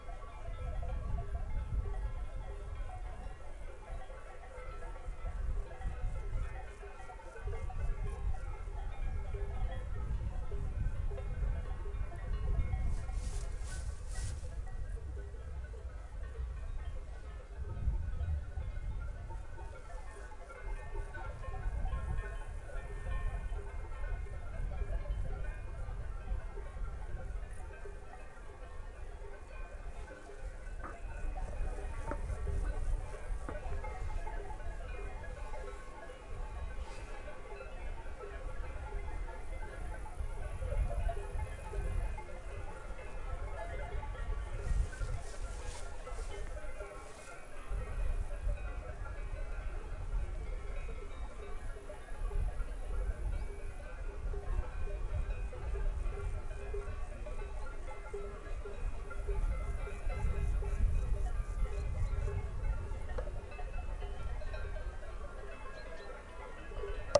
阿尔卑斯山动物铃铛" 牛铃谷
描述：许多牛铃和奇怪的山羊在山谷里吃草，在山坡上产生共鸣。
Tag: 现场录音 牛铃 冥想